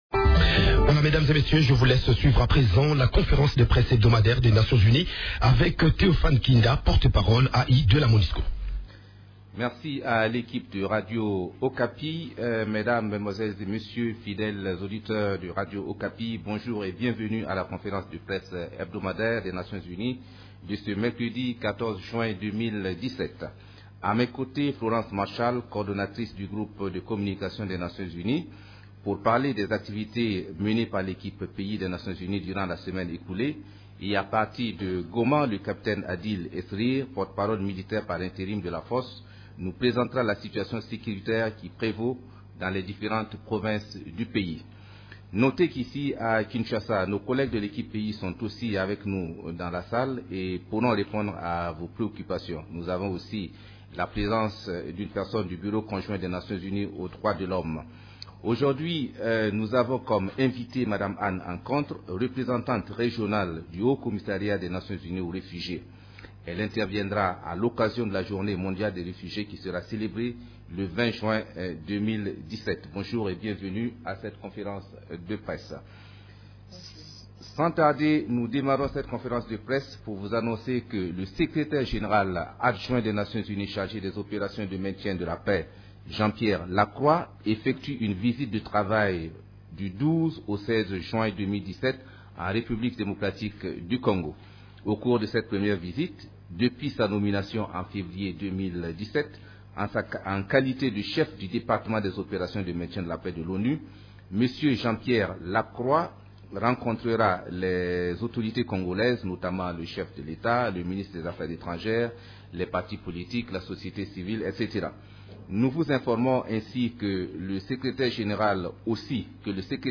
Conférence de presse du 14 juin 2017
La situation sur les activités des composantes de la MONUSCO, les activités de l’Equipe-pays ainsi que de la situation militaire à travers la RDC ont été au centre de la conférence de presse hebdomadaire des Nations unies du mercredi 7 juin à Kinshasa: